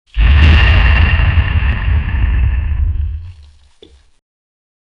A deep rumble builds to a resonant inhale, followed by a guttural, gravelly roar with wet throat vibrations.
Heavy, pounding dinosaur with massive feet crush dry earth and twigs.
heavy-pounding-dinosaur-w-ffxxoiji.wav